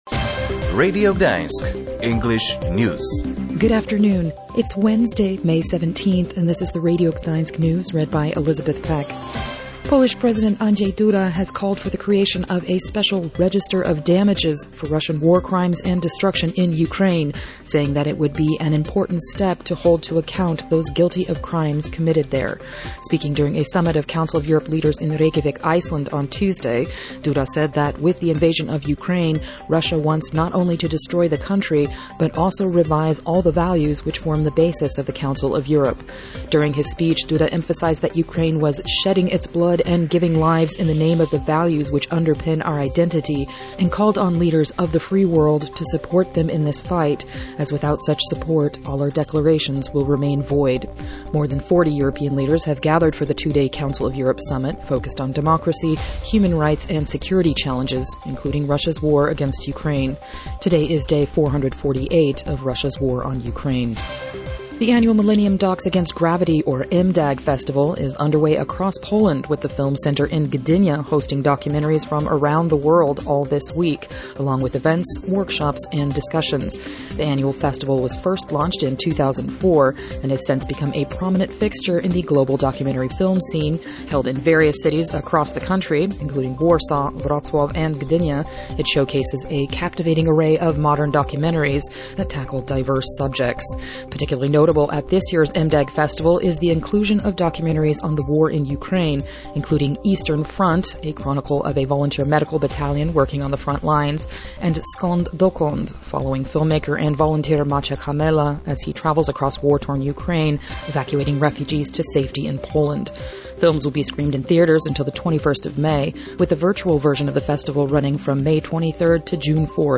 English-News-17.05.mp3